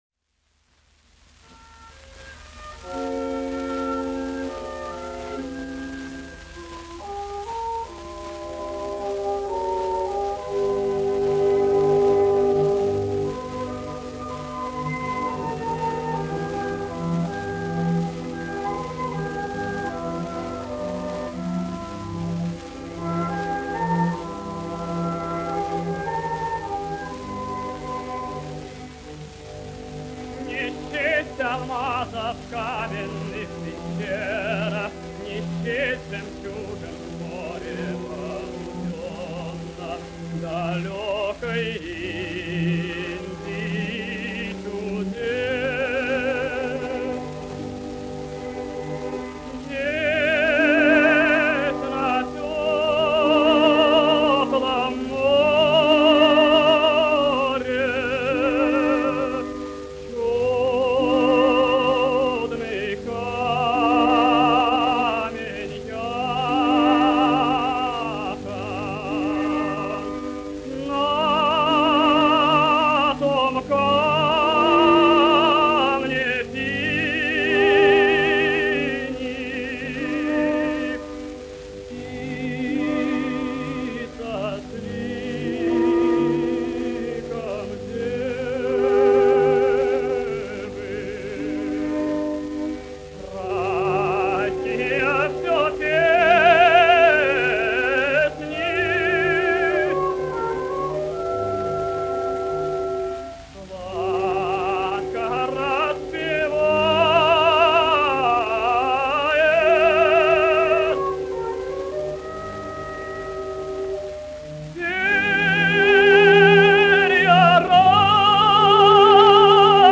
Арии из опер.